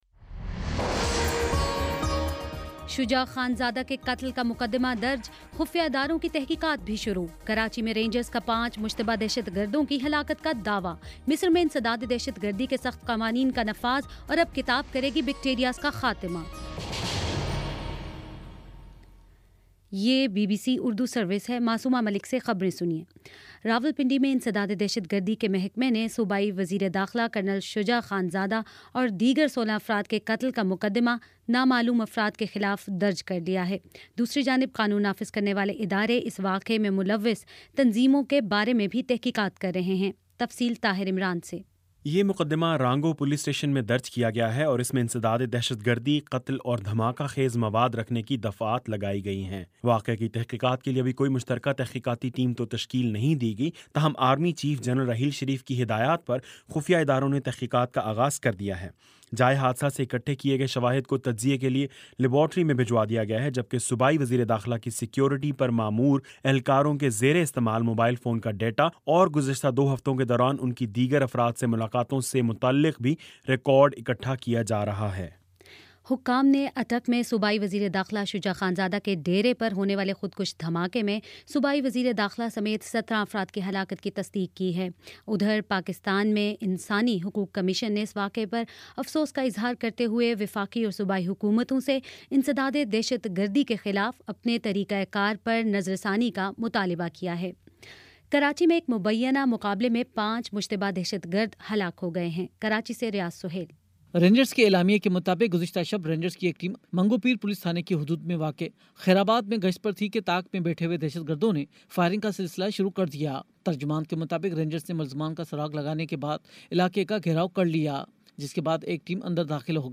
اگست 17: شام پانچ بجے کا نیوز بُلیٹن